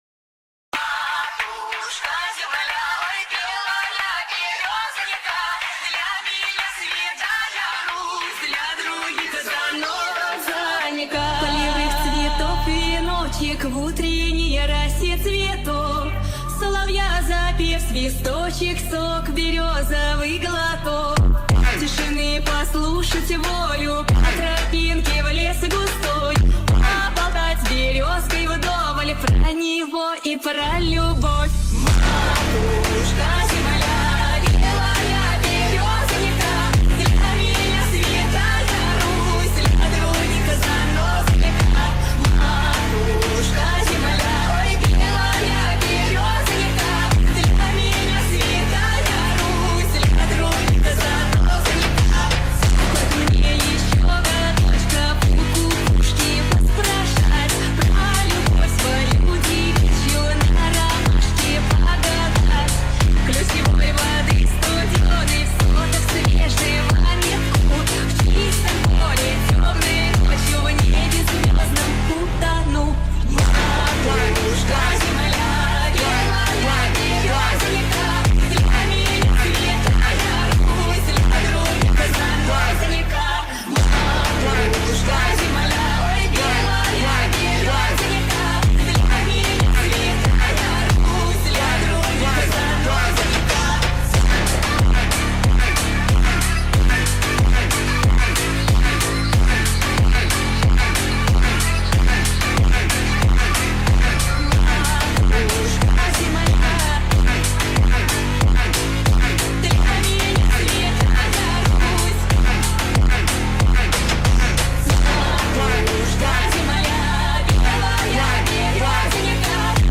ультра фонк тик ток ремикс